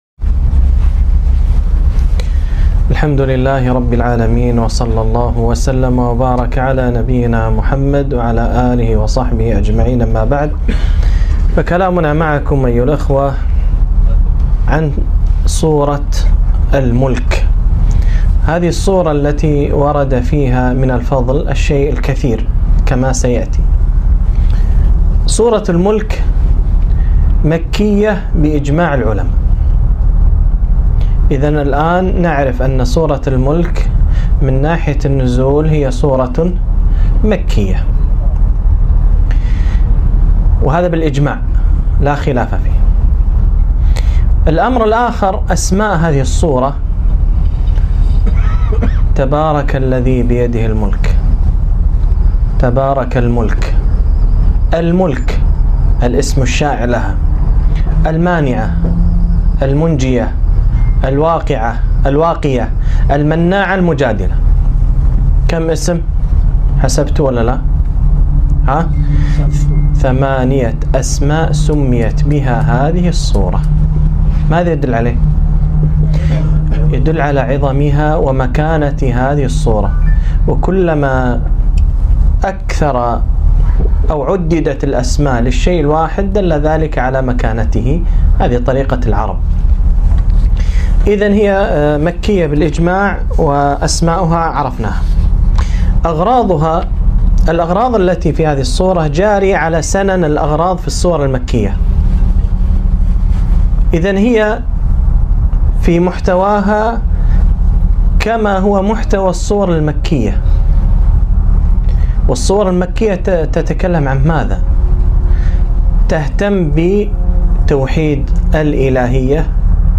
محاضرة - تأملات قرآنية في سورة الملك